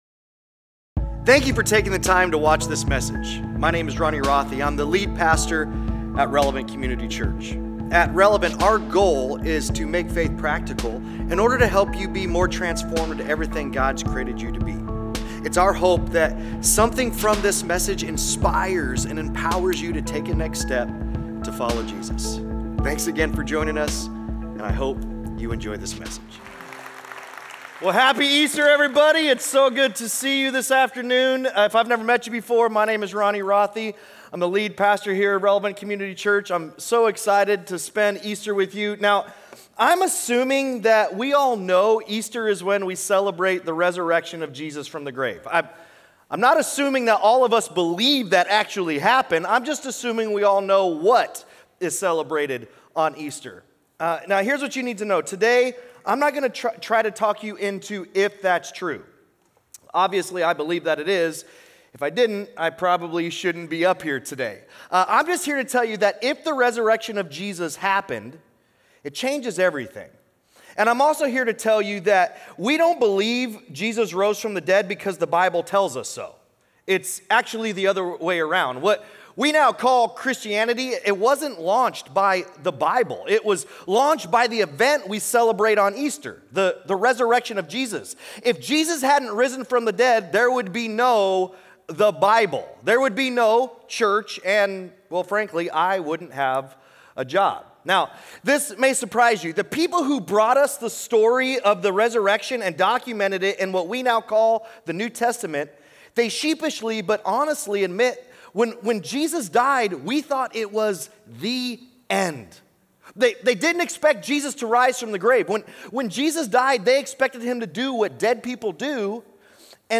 Sunday Sermons Easter Matters Apr 13 2025 | 00:36:50 1x 00:00 / 00:36:50 Subscribe Share Apple Podcasts Spotify Overcast RSS Feed Share Link Embed